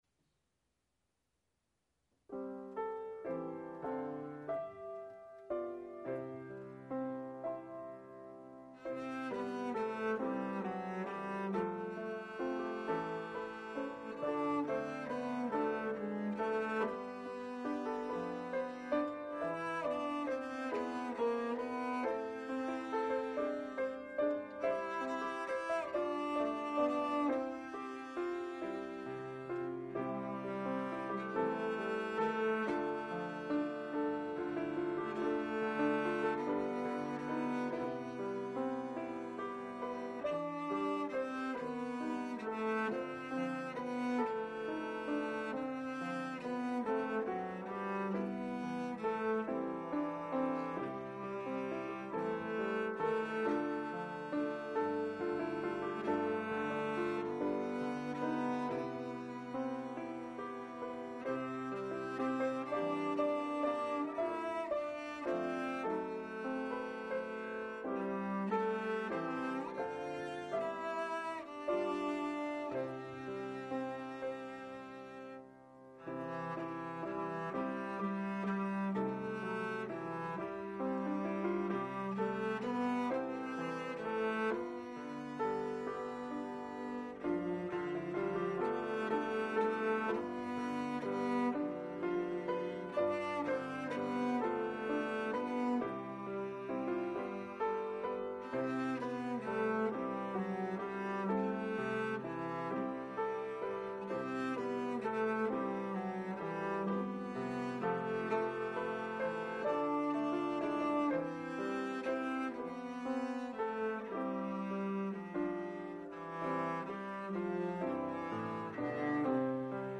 hymns on cello and piano